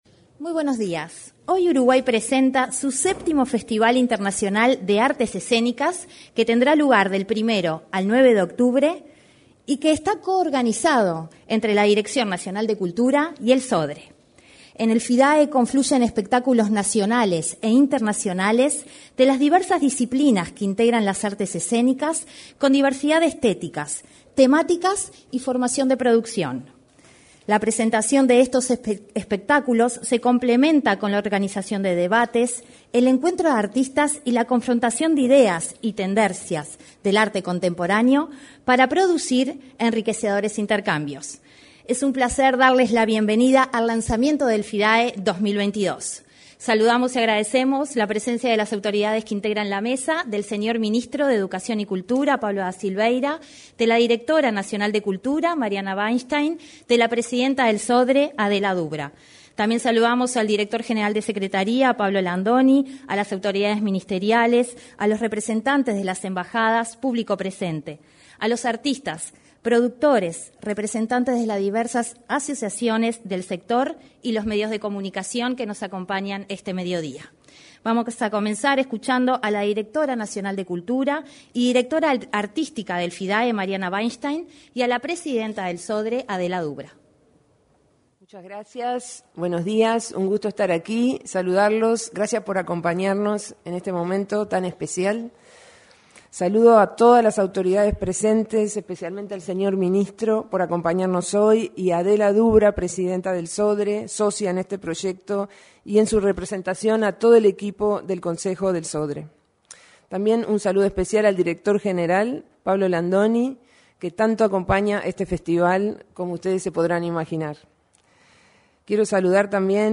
Lanzamiento del Festival Internacional de Artes Escénicas
Lanzamiento del Festival Internacional de Artes Escénicas 26/07/2022 Compartir Facebook X Copiar enlace WhatsApp LinkedIn En la oportunidad se expresaron la directora nacional de Cultura, Mariana Wainstein; la presidenta del Sodre, Adela Dubra, y el ministro de Educación y Cultura, Pablo da Silveira.